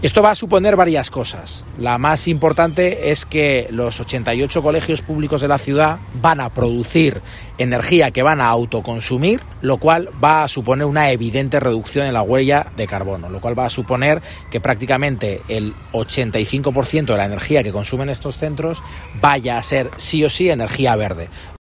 El consejero de Urbanismo, Víctor Serrano, explica el objetivo del sistema de placas fotovoltaicas.